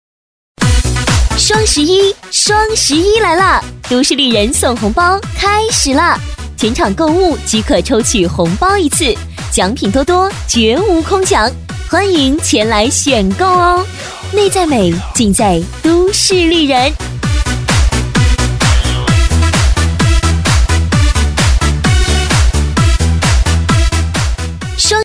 【促销】双十一 内衣女37-激情
【促销】双十一 内衣女37-激情.mp3